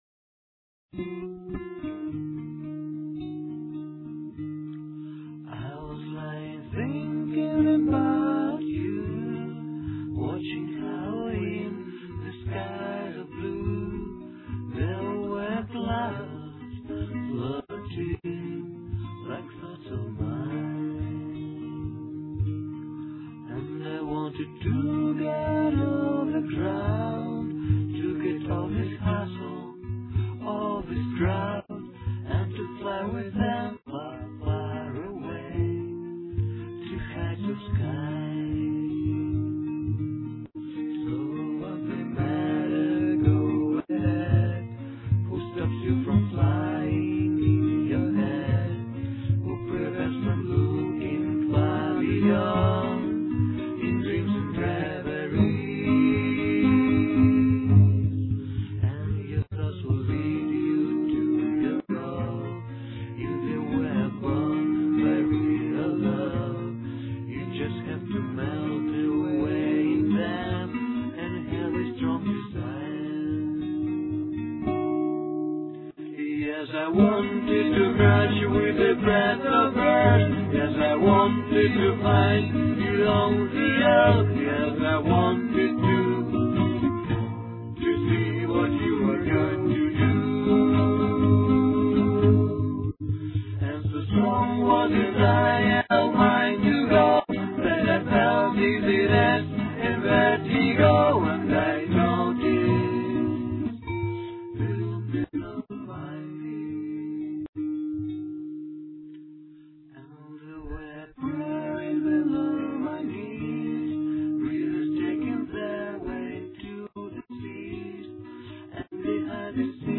Verses (Dm-B-Gm-A-Dm) -- mute picking. Refrain (Gm---B-A-D-G) -- hard beat.